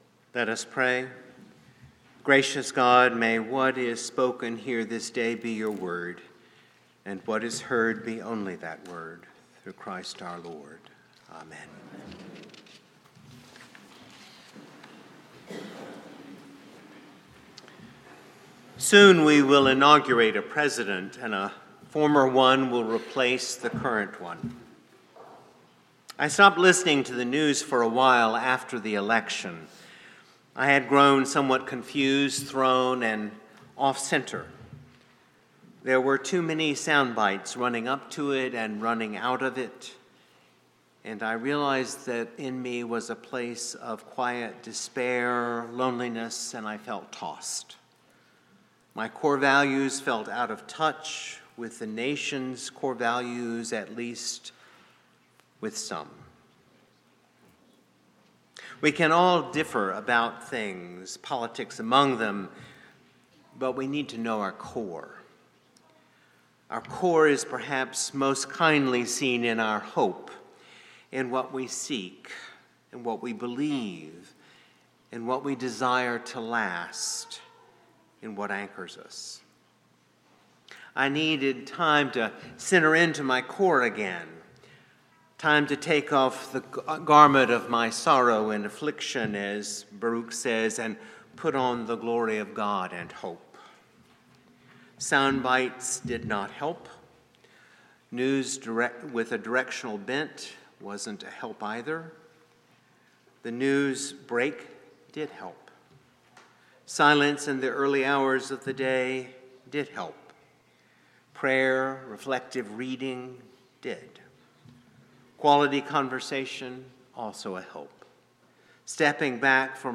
St-Pauls-HEII-9a-Homily-08DEC24.mp3